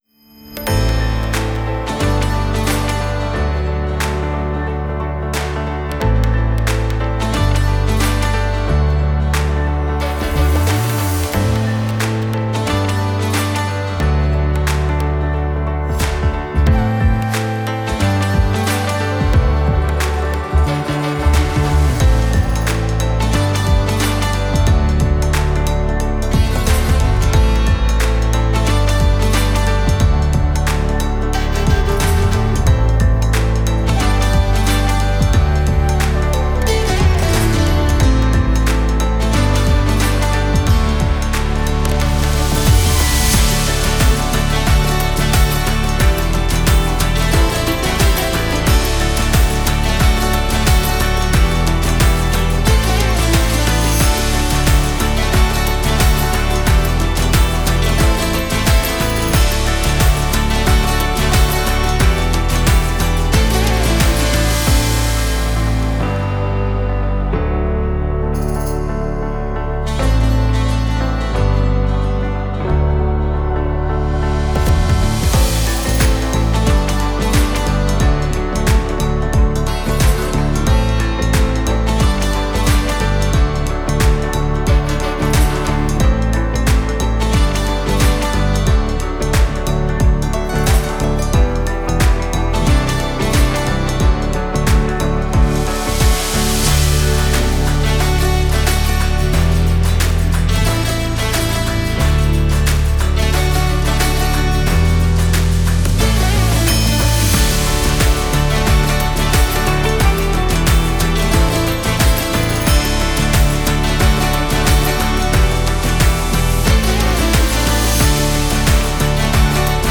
Brand Anthem